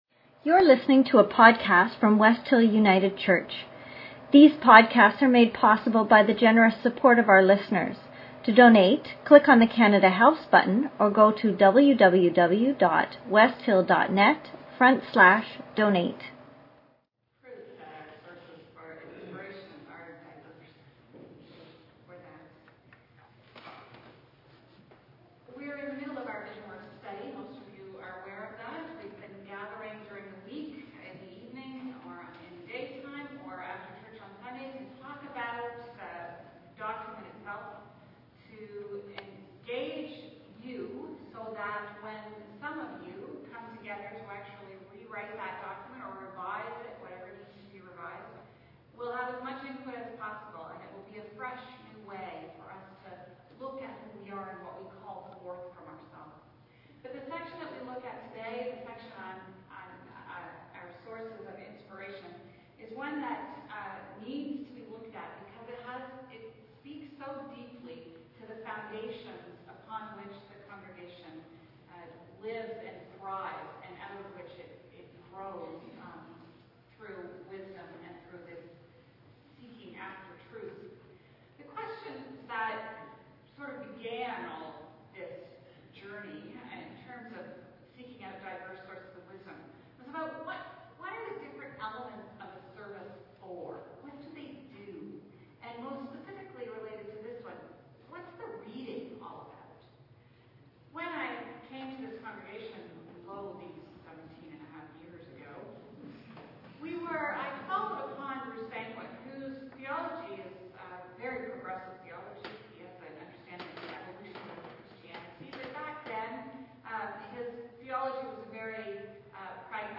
Here's the podcast of the sermon. Sorry about the low sound in the beginning, I promise it gets better right away after that!